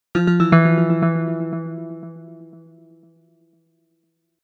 Scifi 14.mp3